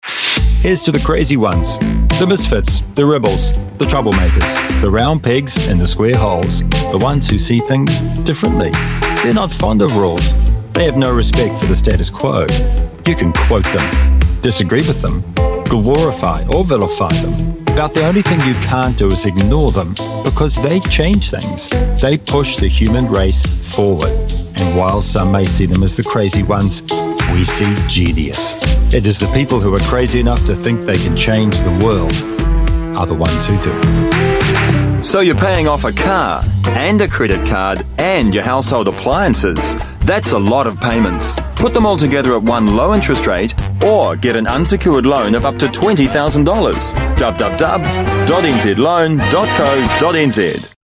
Voice Sample: Voice Demo
We use Neumann microphones, Apogee preamps and ProTools HD digital audio workstations for a warm, clean signal path.